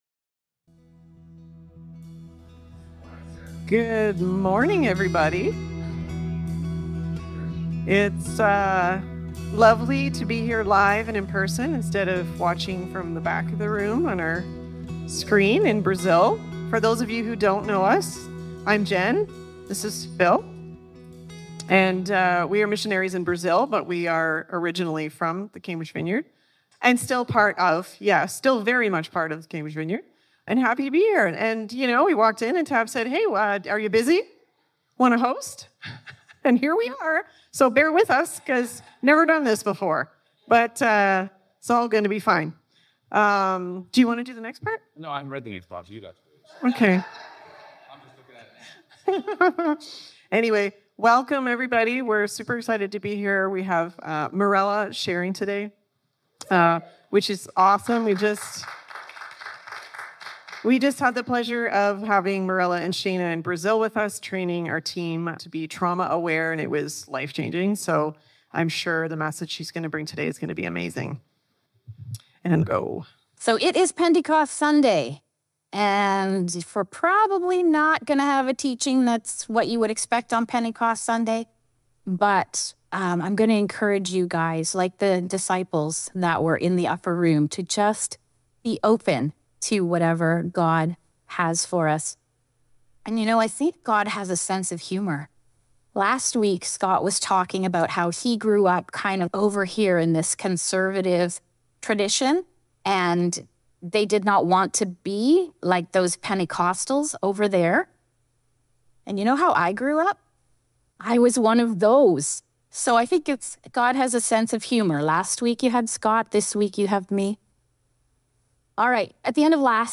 Guest Speaker Service Type: Sunday Morning Circumstances in life can trigger feelings of abandonment